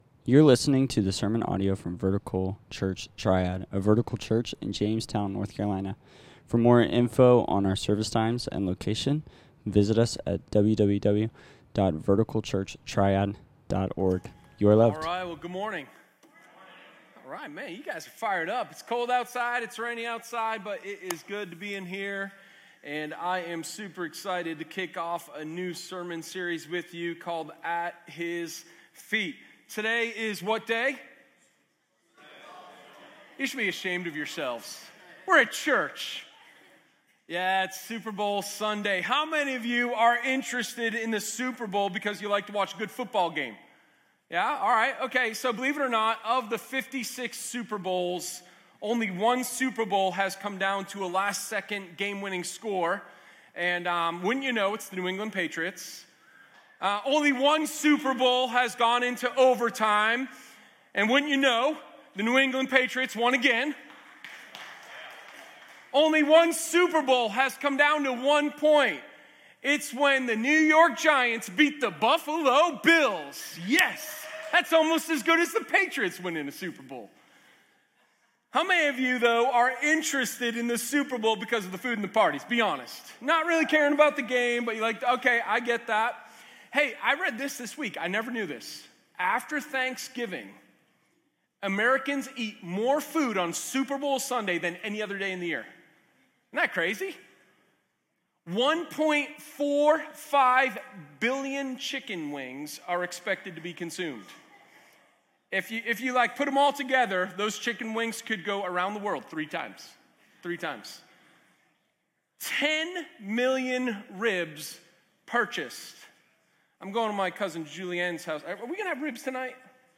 Sermon0212_ThePlaceofAffection.m4a